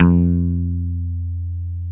Index of /90_sSampleCDs/Roland L-CD701/GTR_Dan Electro/GTR_Dan-O 6 Str